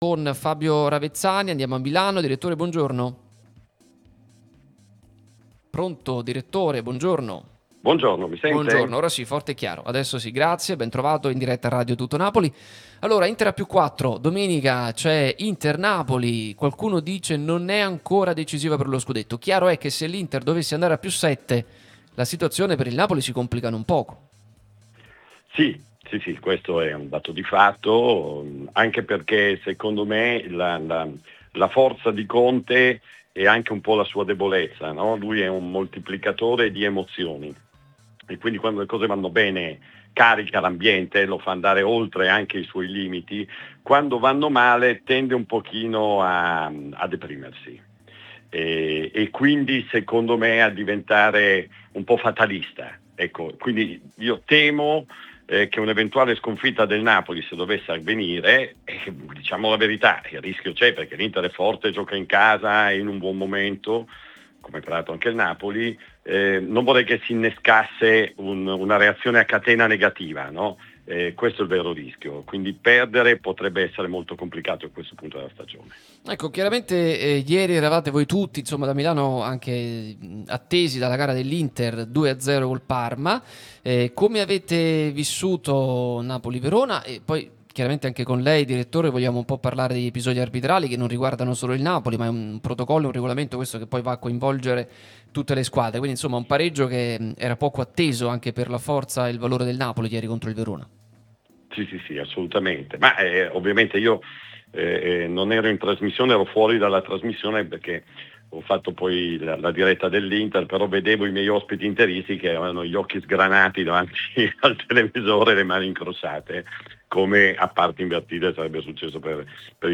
Radio TN Da Milano